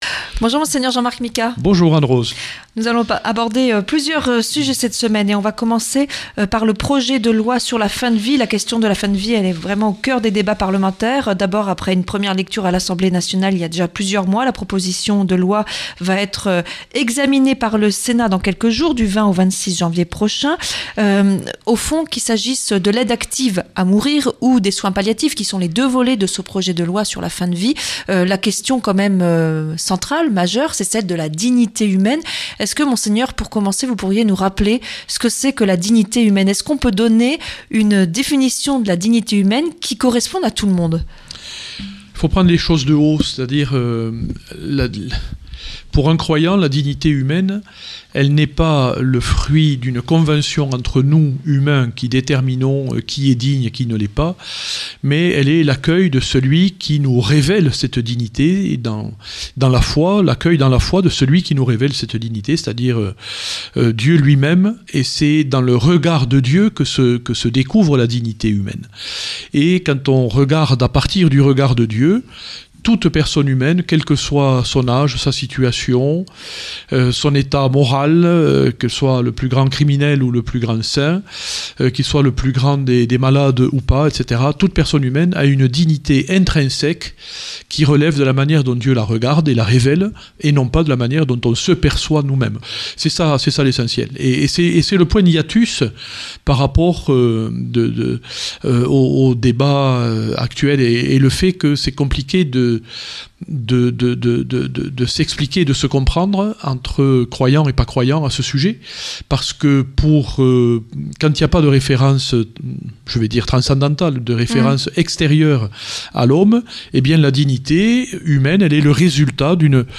L'entrtien avec Mgr Jean-Marc Micas